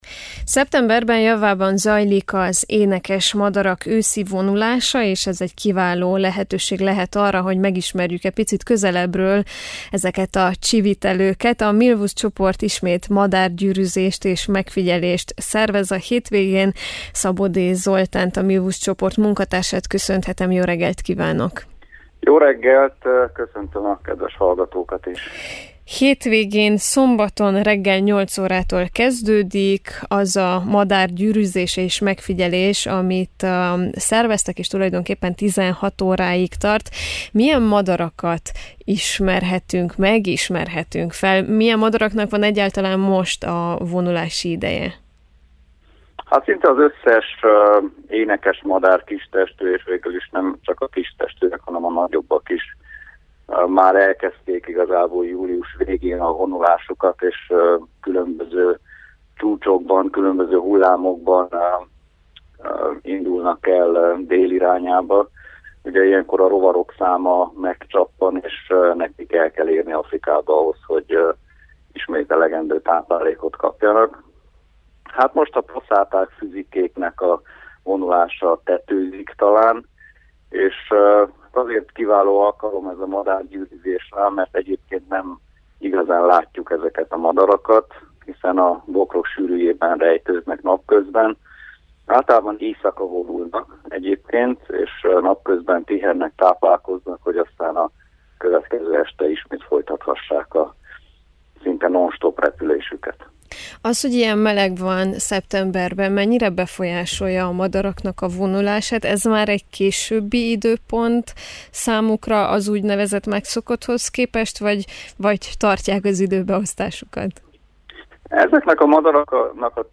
Műsorvezető